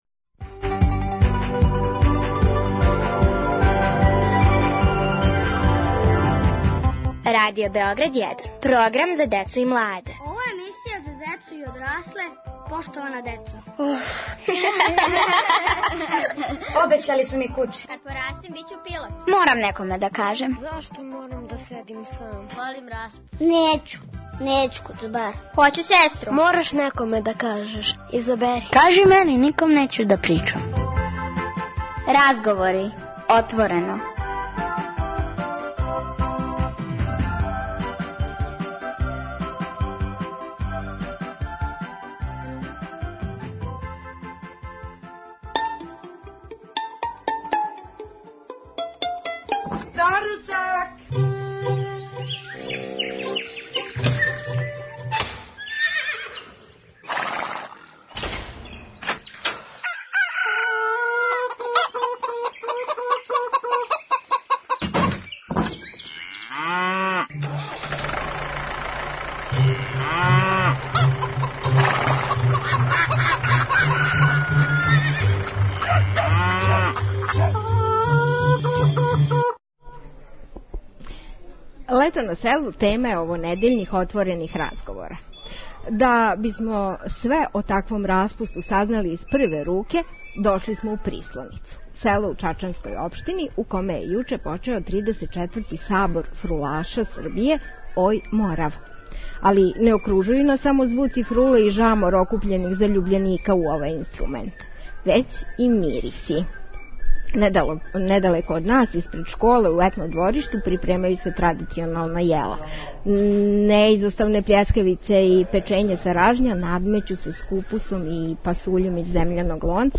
Са Сабором фрулаша у позадини, поштована деца из Прислонице и њихови гости, ове недеље разговарују о летњем распусту на селу.
Поштована децо - уживо из Прислонице